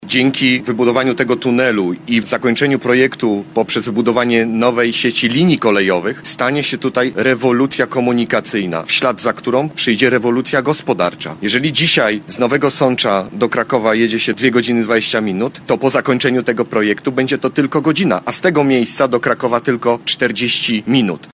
Tymczasem od czerwca trwa też drążnie równoległego, głównego tunelu o długości prawie 4 km, który wykonuje bliźniacza maszyna „Jadwiga” – mówił w Męcinie minister infrastruktury Dariusz Klimczak.